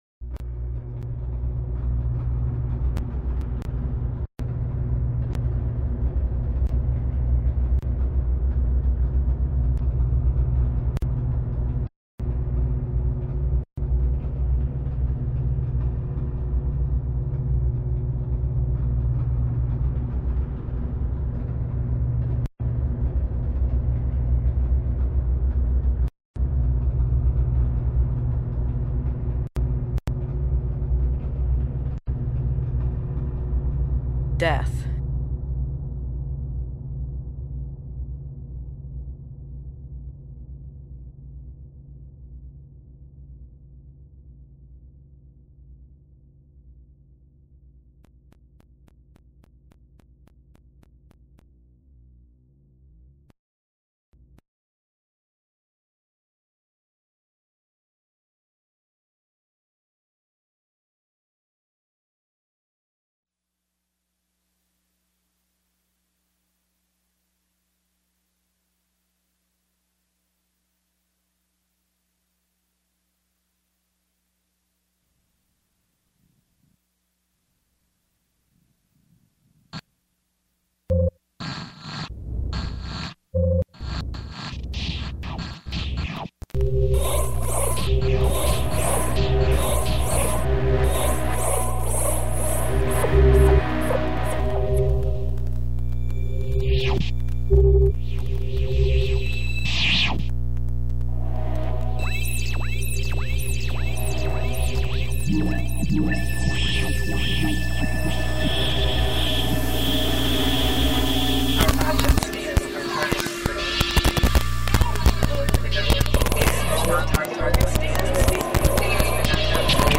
They are enhanced sound pieces based upon Harryman’s readings. 1